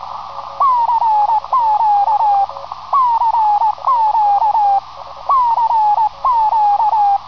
Chirpy CQ
Here is an example of chirpy CW from a certain CM (Cuba) station. I've heard worse, but this one definitely has character.
chirpy_cq.wav